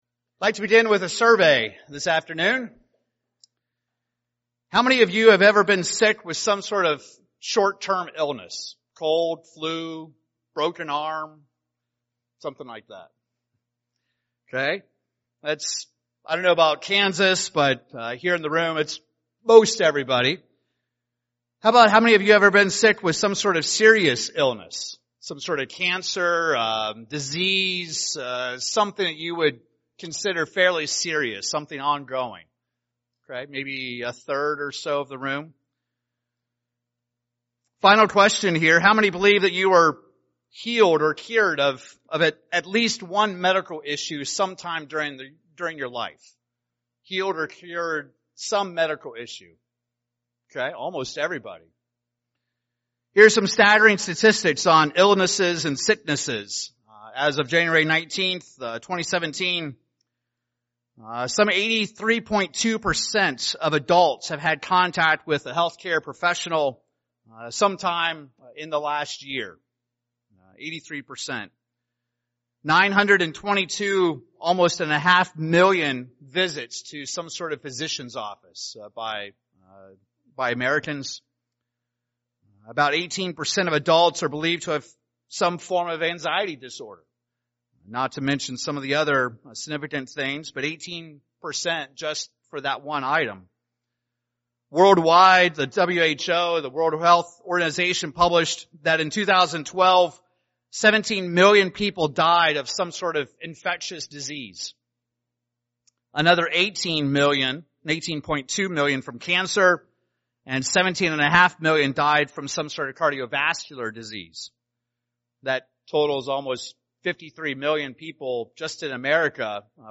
How do you ask God for healing? This sermon will review the key concepts and scriptures about healing.